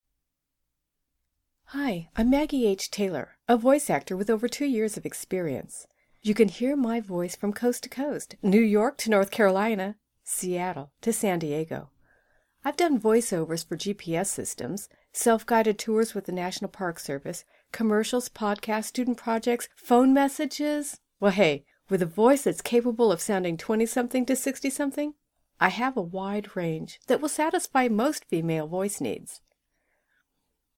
Voice Over Introduction